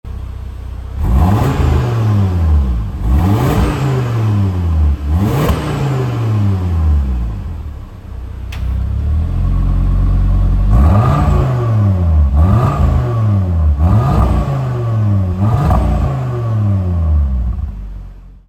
kylami-ztc-revs.mp3